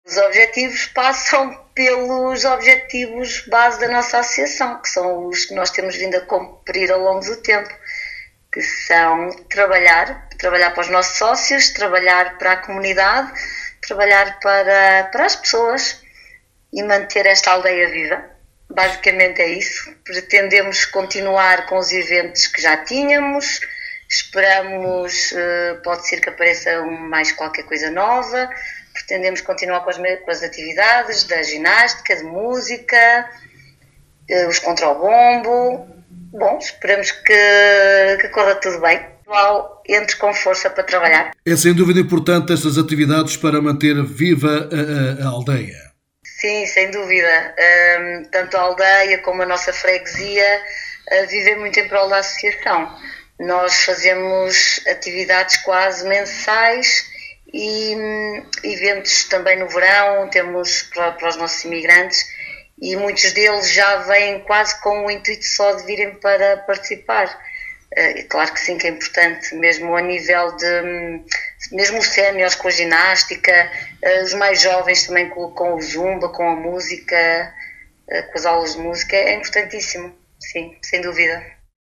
em entrevista à Alive FM, referiu os objetivos para mais um mandato, “dar continuidade às atividades desenvolvidas pela Associação em prol dos associados e da comunidade…”.